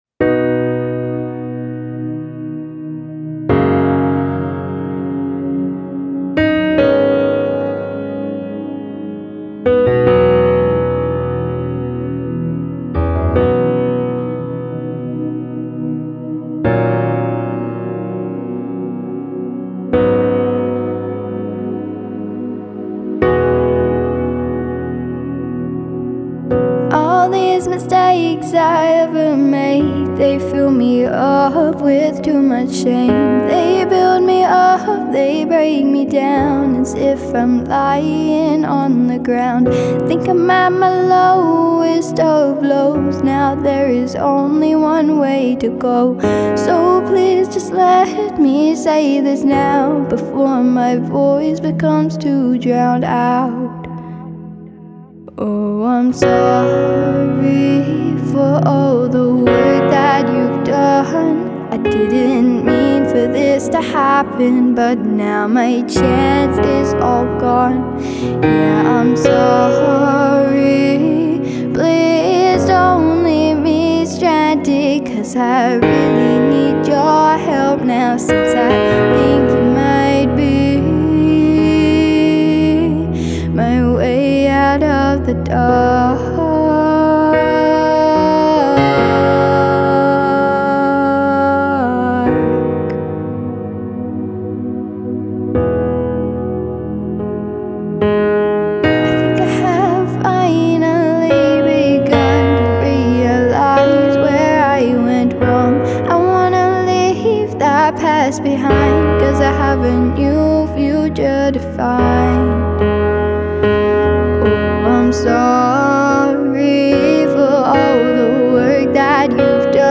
It is a Pop Ballad performed with just piano and vocals.
clean pop style that carries country and rock undertones